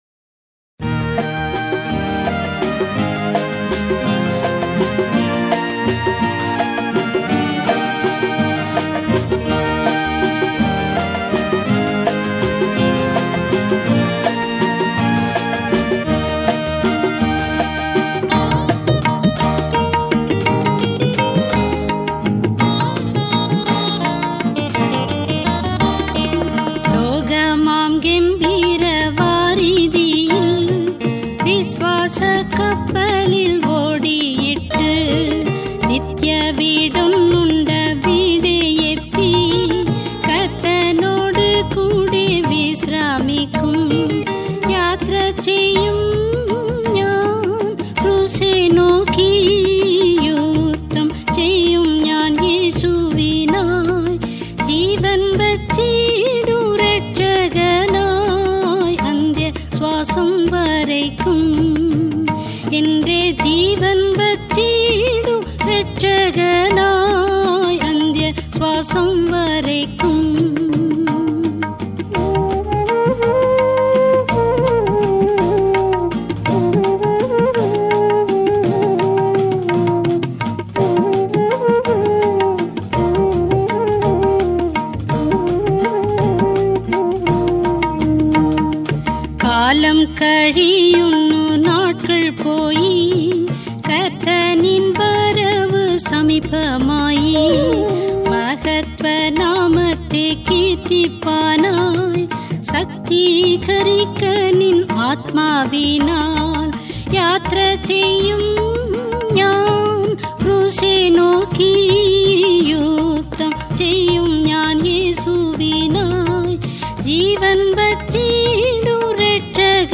gospel album
Royalty free Christian music.